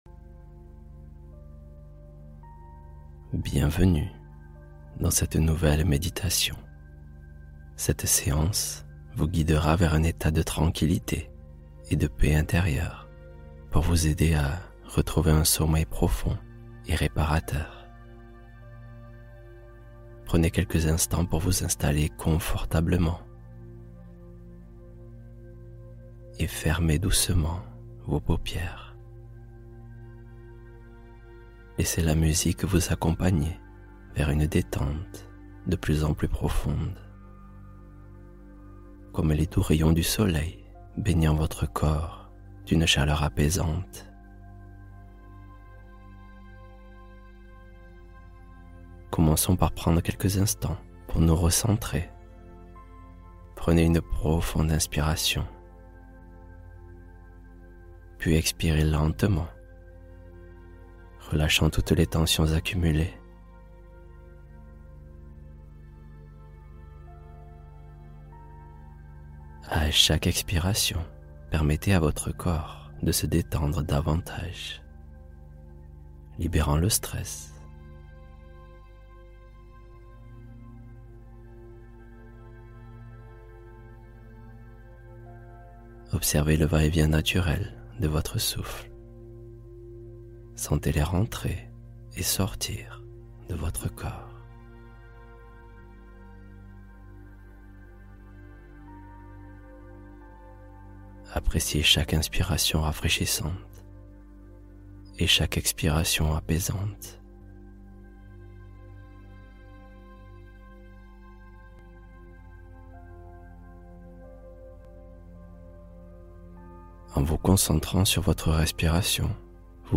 Solution Nuit : Histoire guidée contre l'agitation du soir